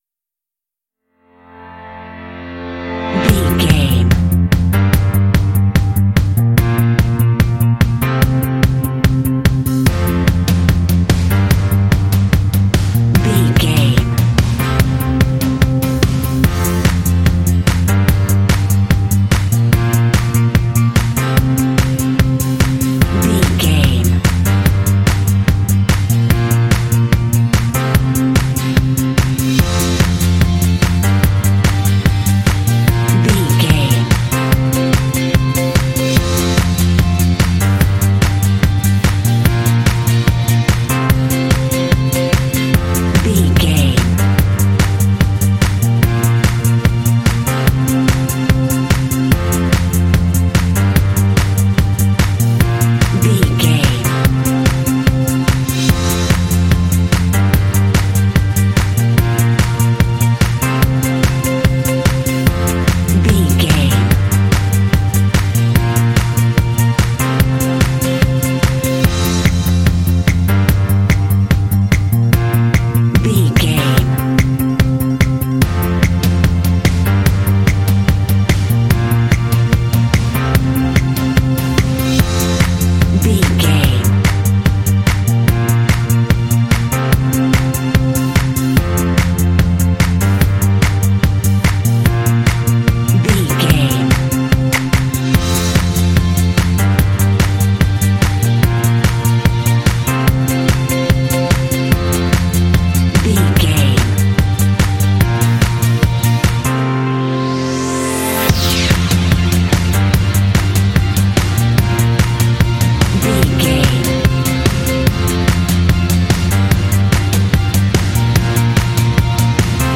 Bright and motivational music with a great uplifting spirit.
Ionian/Major
uplifting
happy
bouncy
groovy
drums
strings
piano
electric guitar
bass guitar
rock
indie